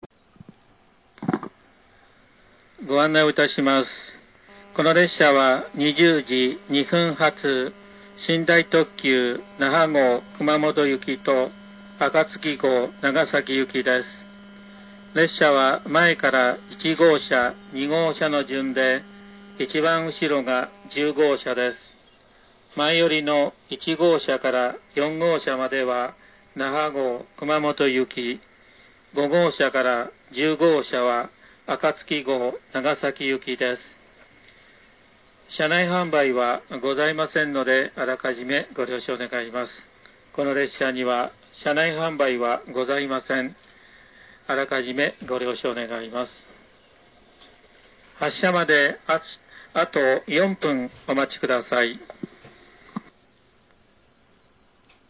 放送については基本的に車内チャイムが鳴った放送を掲載しますが、一部チャイム無しの放送も載せてありますのでご了承ください。
京都発車前京都発車後